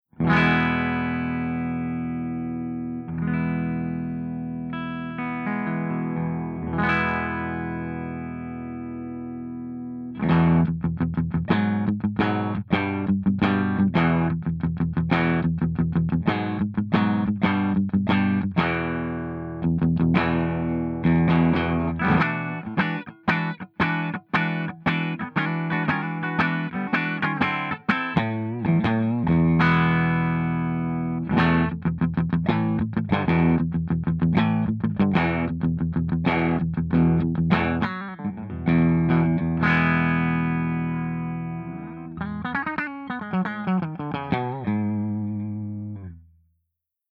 067_FENDERSUPERREVERB_STANDARD_P90.mp3